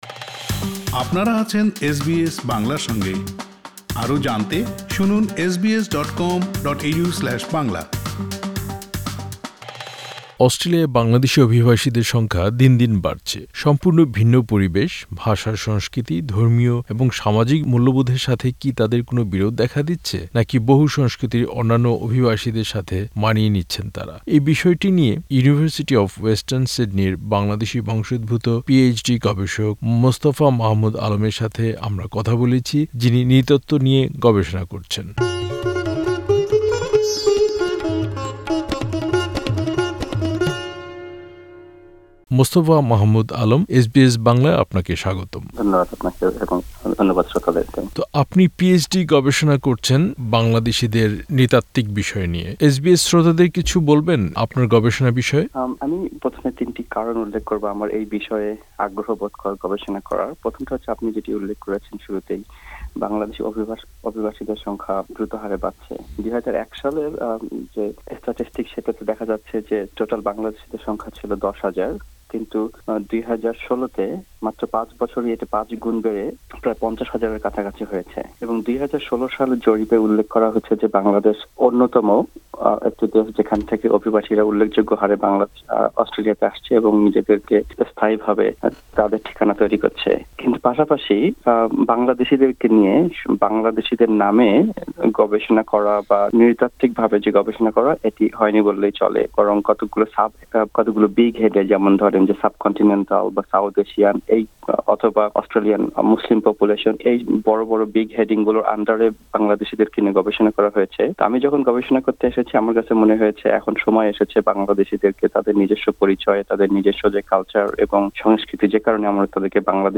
আলাপচারিতা।